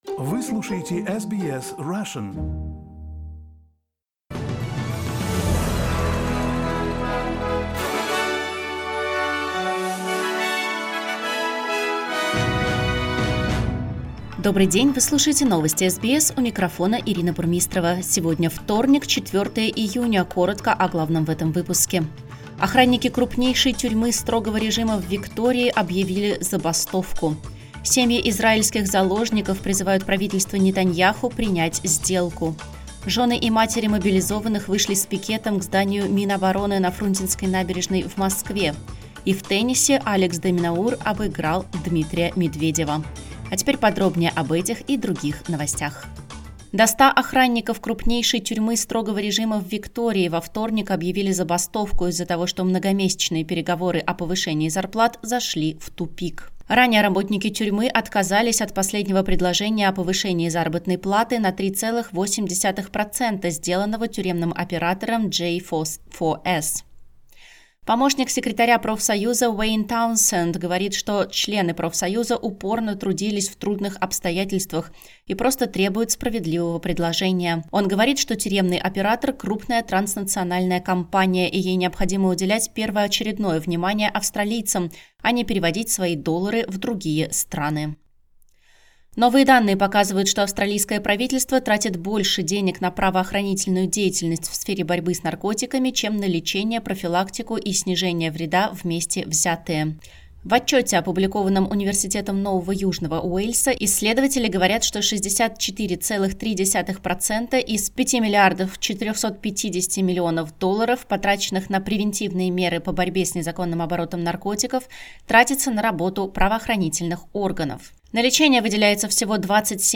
SBS News in Russian — 04.06.2024